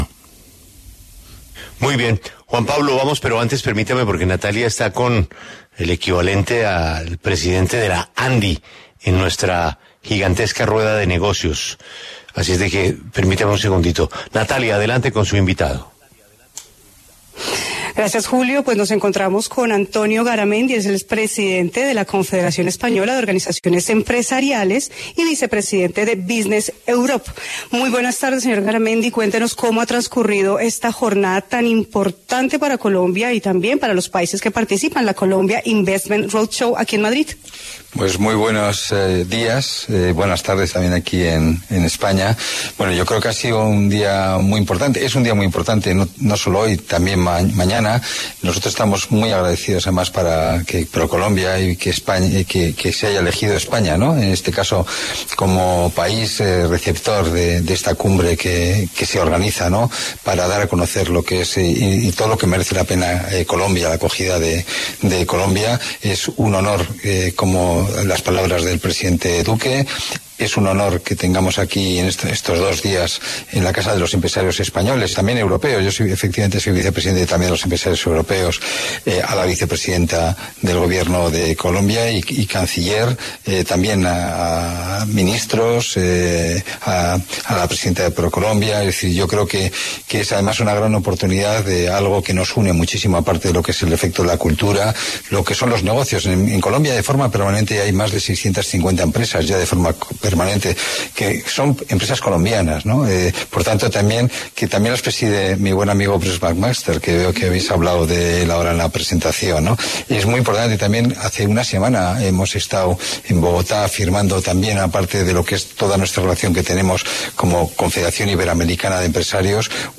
Antonio Garamendi, presidente de la Confederación Española de Organizaciones Empresariales y vicepresidente de Business Europa, habló en La W en el marco del Colombia Investment Roadshow.
En el encabezado escuche la entrevista completa con Antonio Garamendi, presidente de la Confederación Española de Organizaciones Empresariales y vicepresidente de Business Europa.